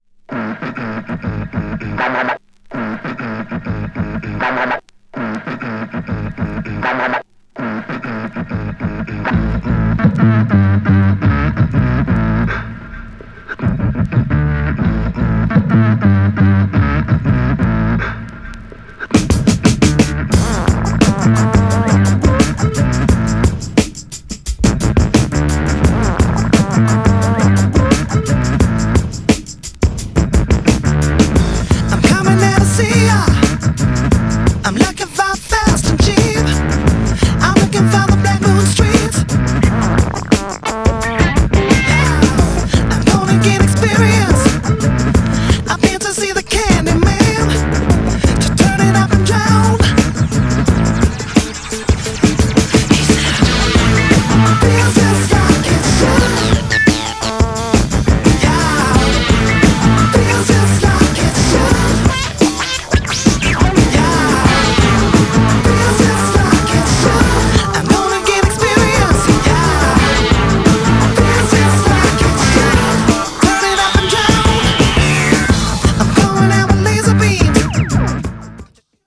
以前のようなキャッチーさはなくなったACID JAZZの進化系とも言える
パンクロックとのミクスチャーなサウンド。
GENRE R&B
BPM 101〜105BPM